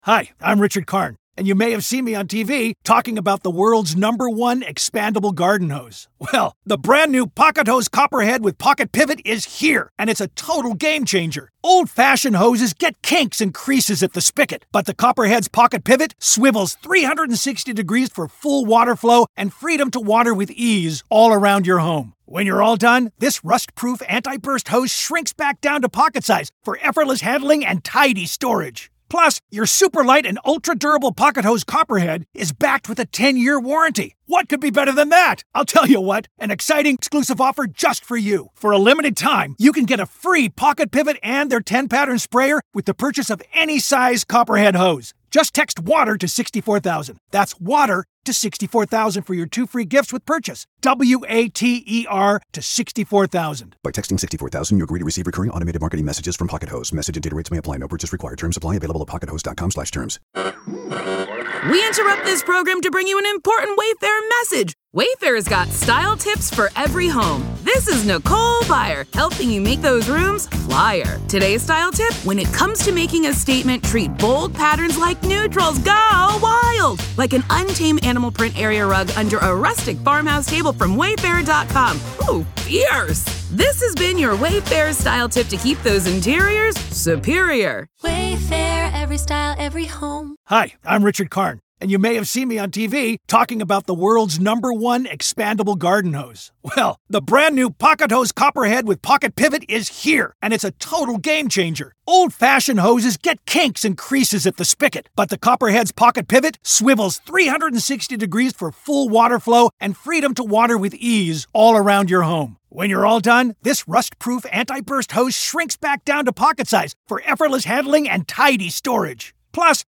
NM v. Alec Baldwin Manslaughter Trial- Day 2 Part 3